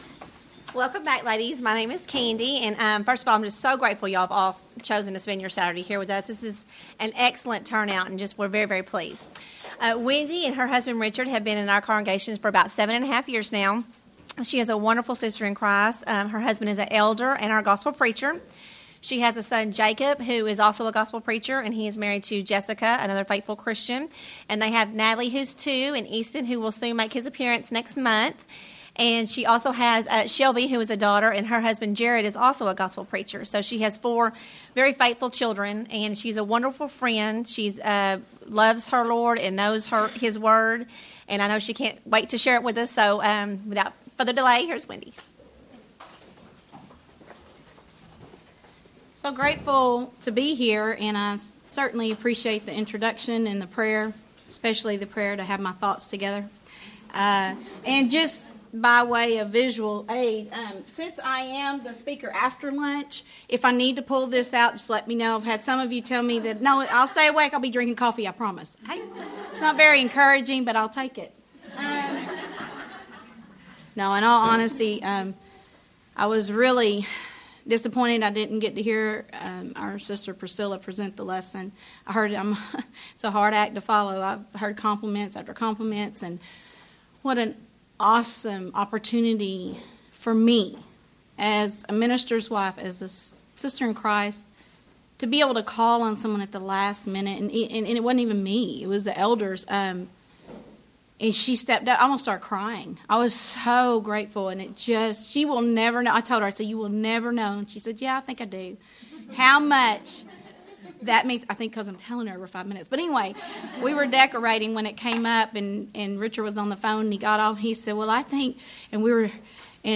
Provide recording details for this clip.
Event: Make Me A Servant